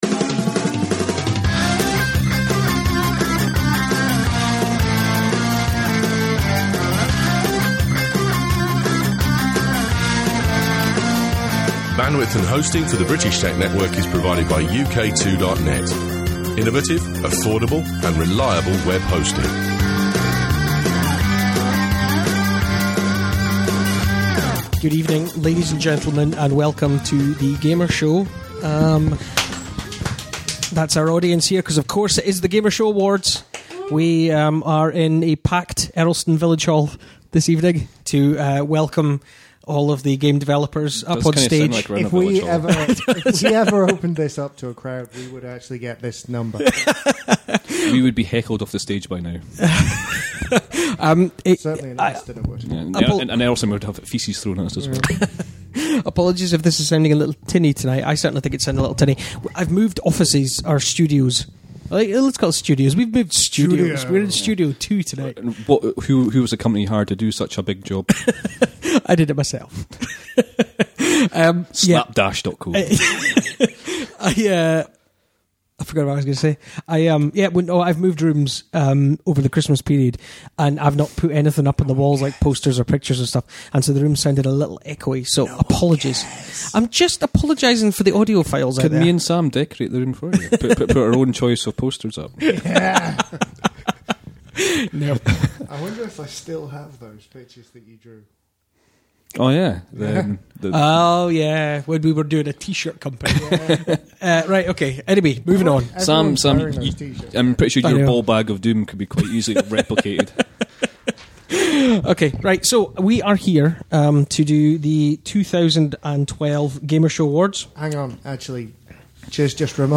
In a slightly more relaxed and unstructured version of the Gamer Show (read as unplanned as we are all in holiday mode). The guys discuss the results for the Gamer Show awards and games they have been playing recently.